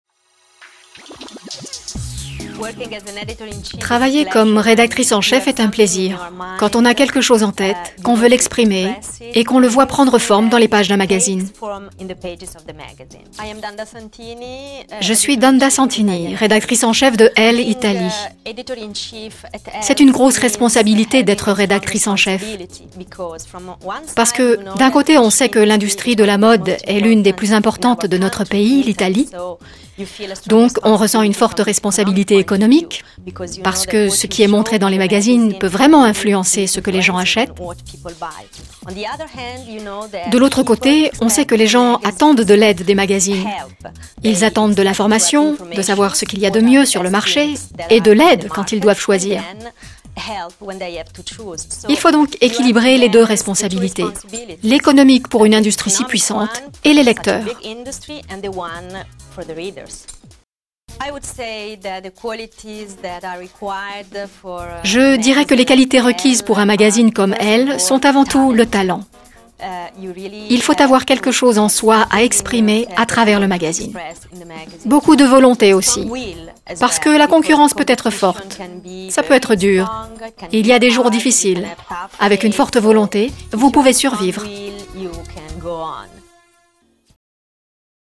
pub voiture
Comédienne